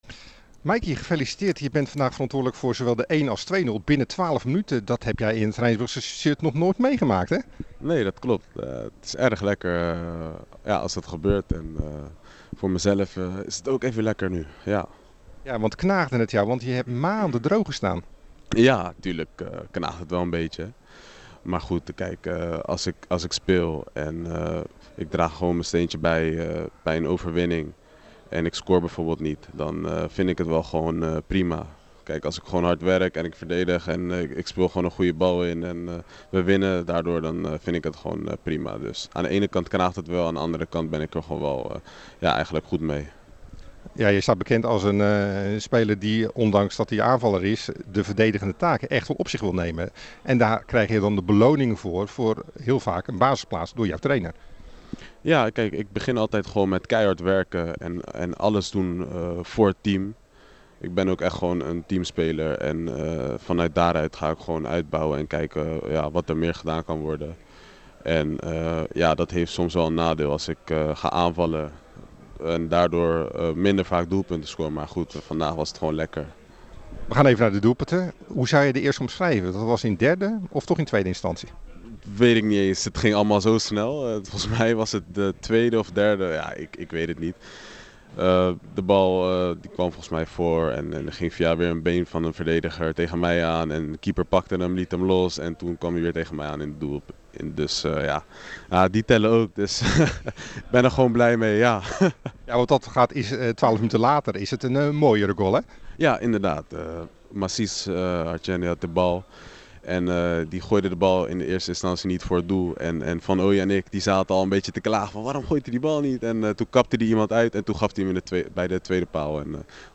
Het gehele interview